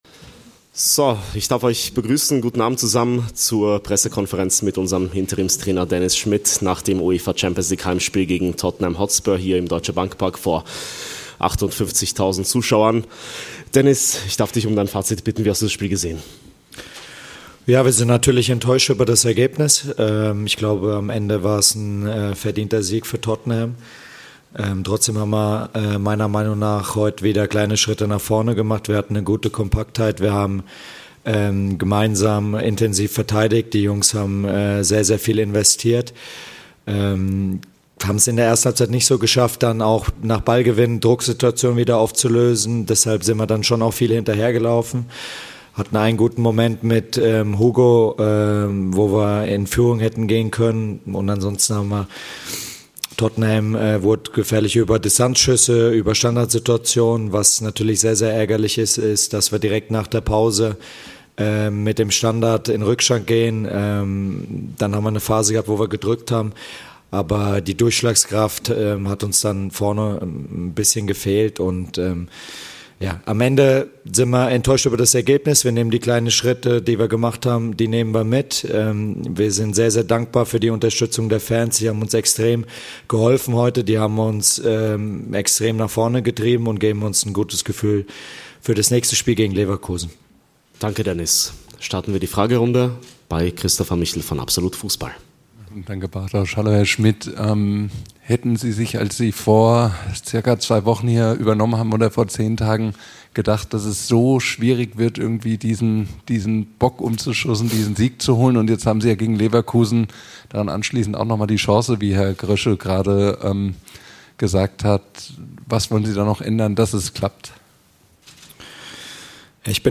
"Ärgerlicher Rückstand" I Pressekonferenz nach Eintracht - Tottenham Hotspur I Champions League